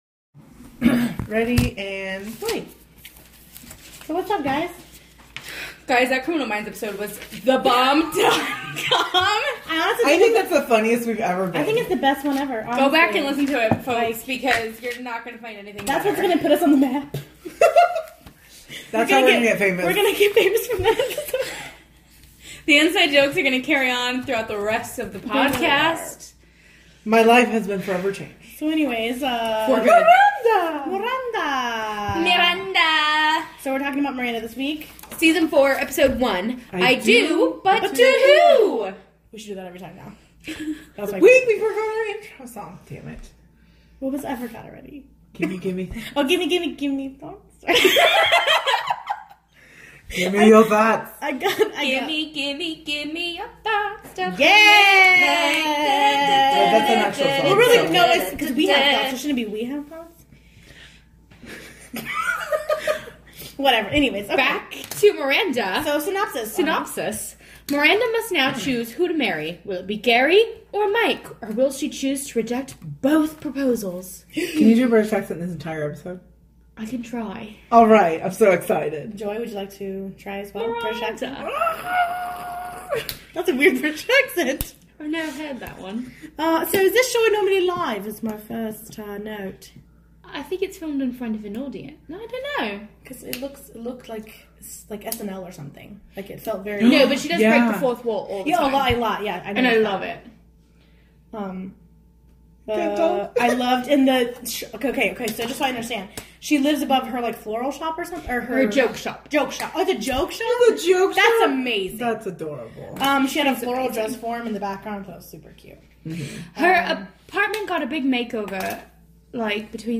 Also if you like people going in and out of accents then this is the episode is for you.